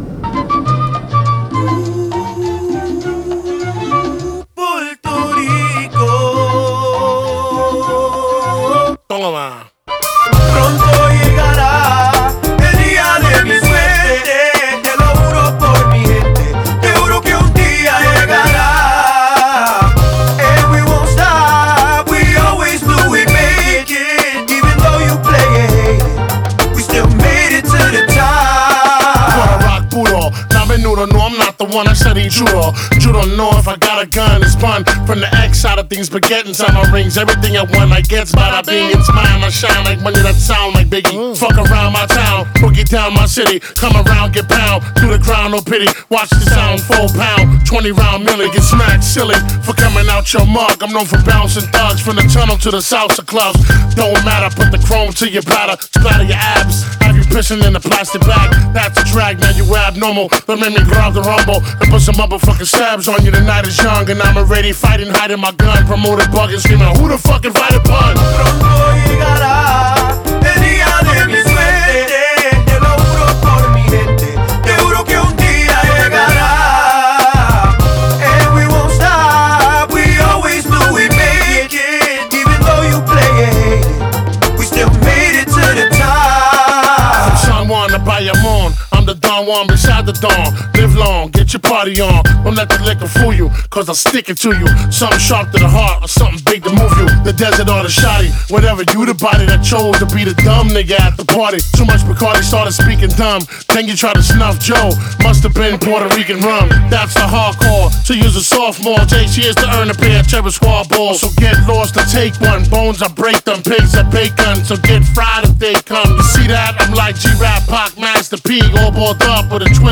イントロがとにかくかっこいいです。
プエルトリコの雰囲気も感じられる1曲になってます。